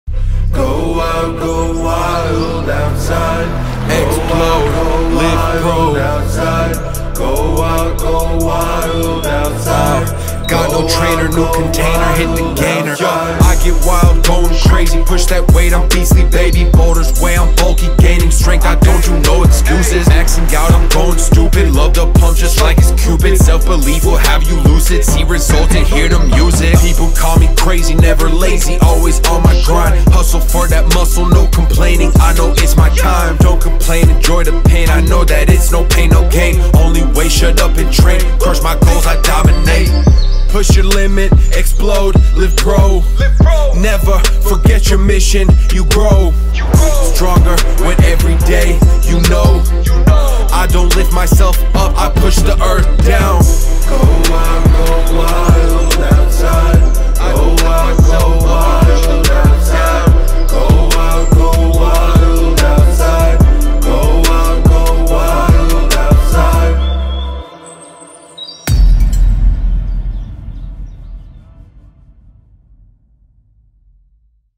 RAP UND BEAT BEISPIELE